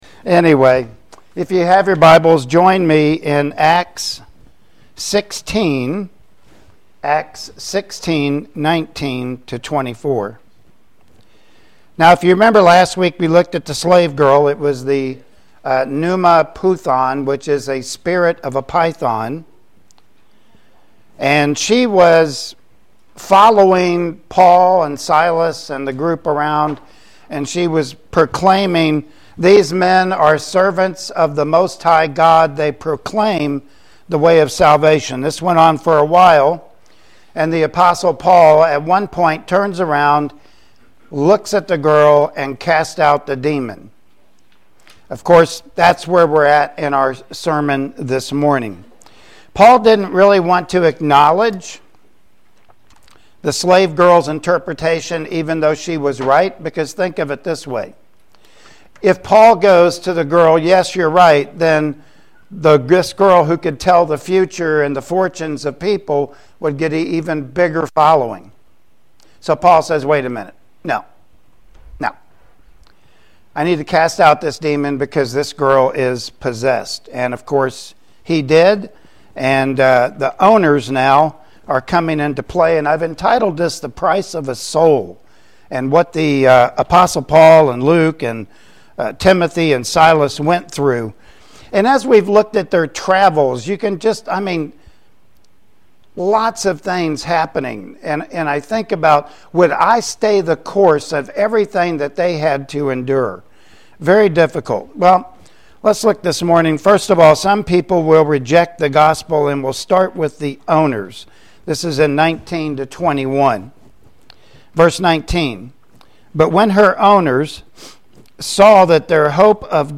Acts 16:19-24 Service Type: Sunday Morning Worship Service Topics: Cost of Following Christ « Python Midnight Cry